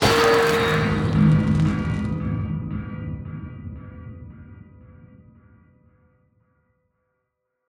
Trident thunder 2.ogg